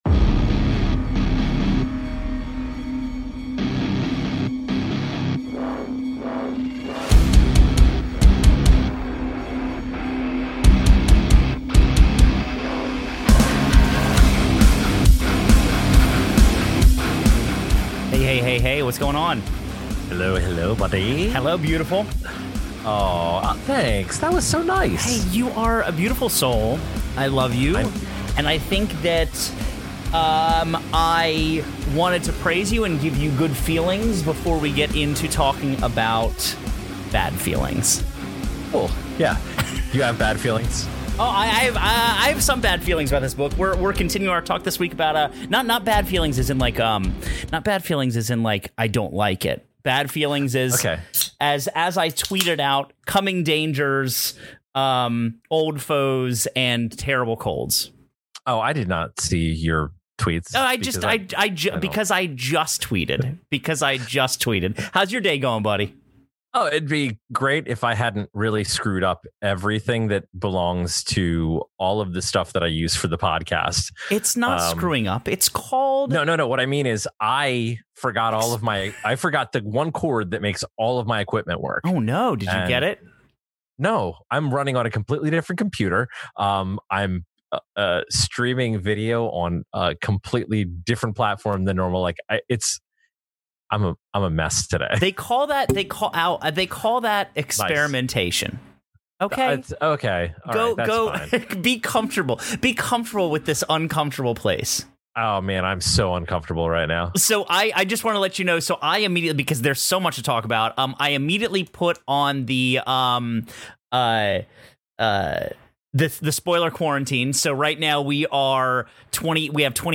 (Due to the nature of this week's recording, there may be a few minor audio glitches.
The guys continue to wrestle with the nuances of live episodes, and find that sometimes maybe we should invest in a pop filter.